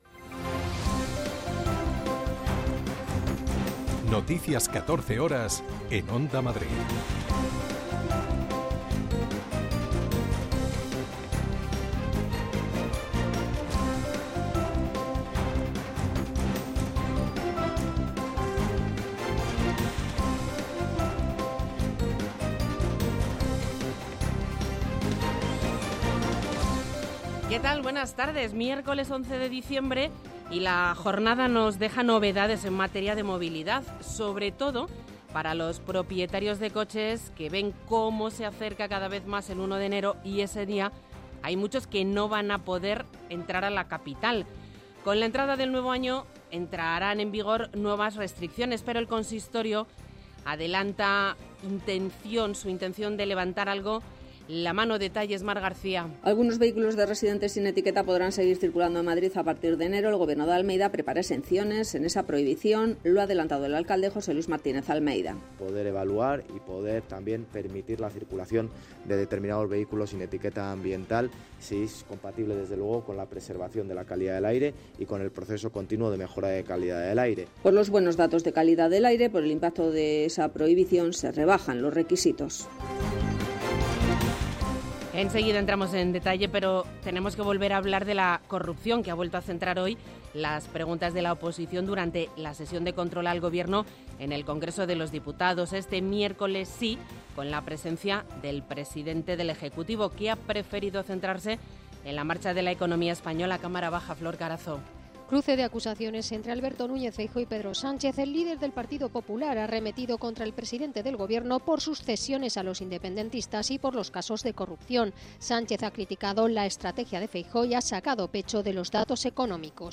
Noticias 14 horas 11.12.2024
60 minutos de información diaria con los protagonistas del día, y conexiones en directo en los puntos que a esa hora son noticia